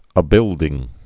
(ə-bĭldĭng)